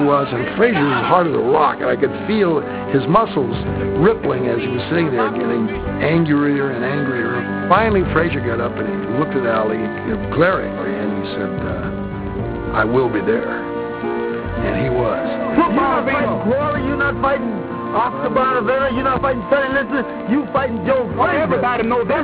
Press conference for Ali-Frazier 1